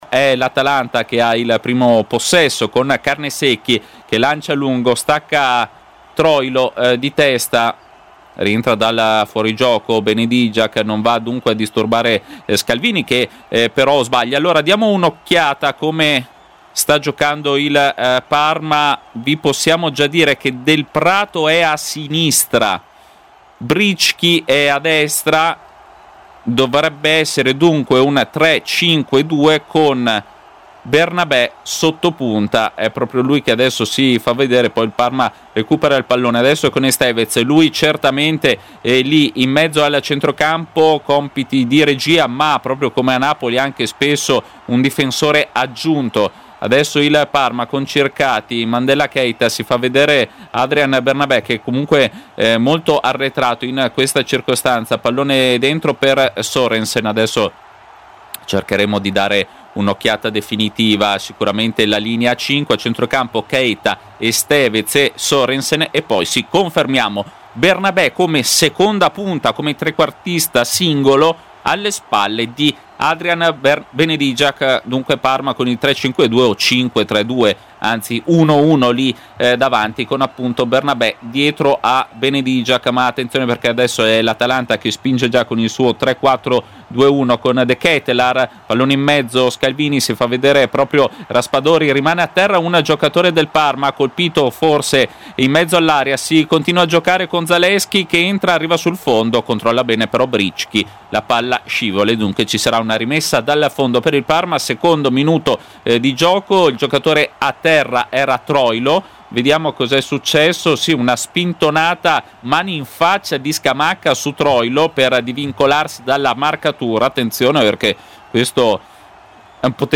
Radiocronache Parma Calcio Atalanta - Parma 1° tempo - 25 gennaio 2026 Jan 25 2026 | 00:45:59 1x 00:00 / 00:45:59 Subscribe Share RSS Feed Share Link Embed